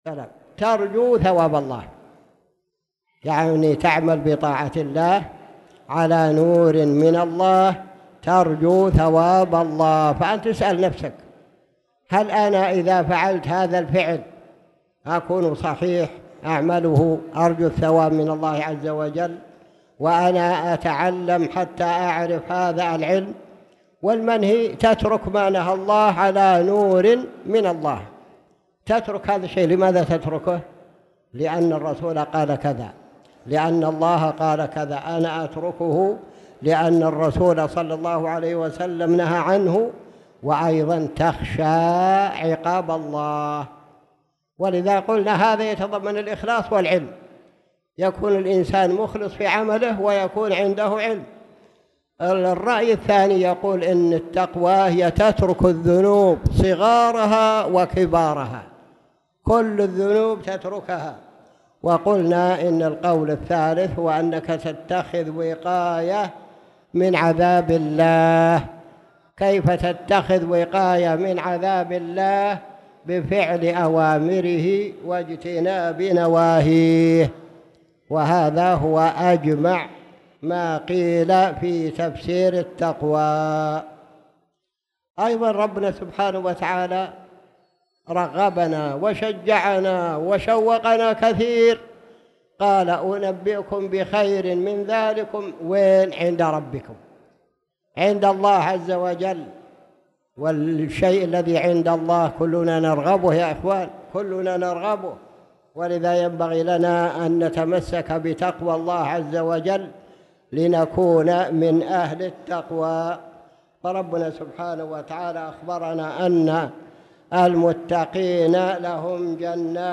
تاريخ النشر ١٧ ربيع الثاني ١٤٣٨ هـ المكان: المسجد الحرام الشيخ